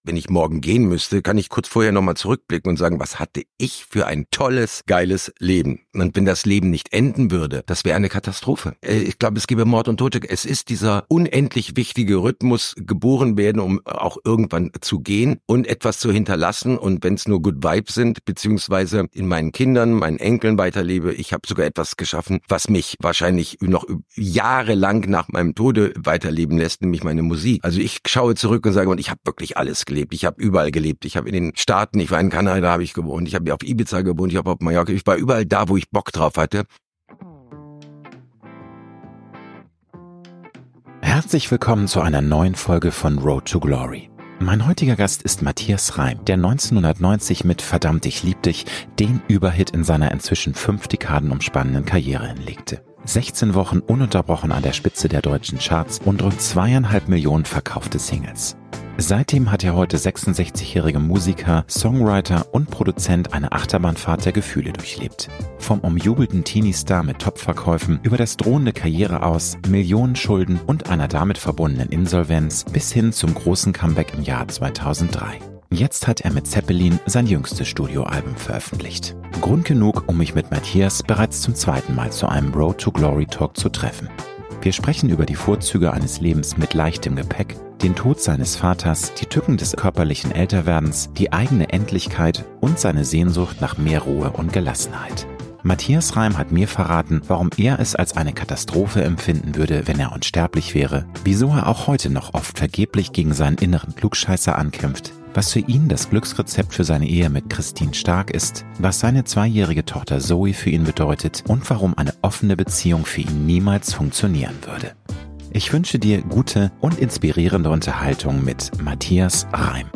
Grund genug, um mich mit Matthias bereits zum zweiten Mal zu einem Road-to-Glory-Talk zu treffen. Wir sprechen über die Vorzüge eines Lebens mit leichtem Gepäck, den Tod seines Vaters, die Tücken des körperlichen Älter werdens, die eigene Endlichkeit und seine Sehnsucht nach mehr Ruhe und Gelassenheit.